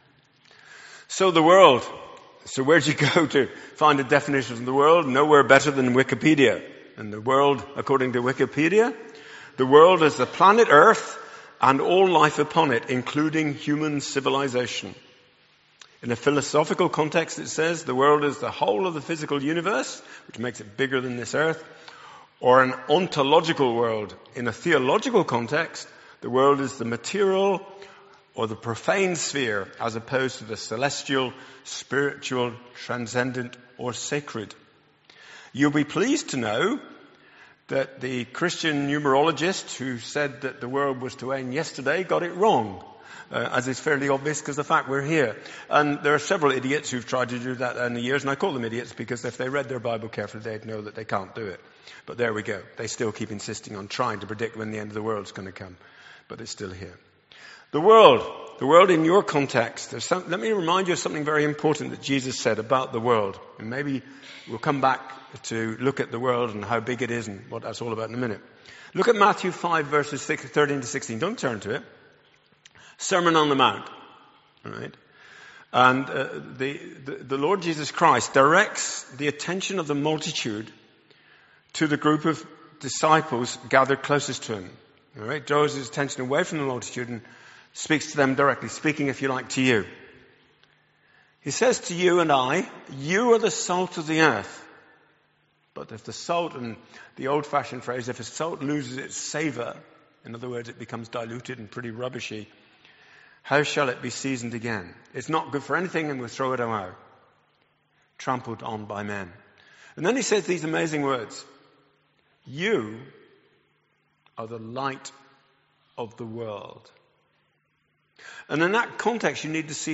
Audio file of the service.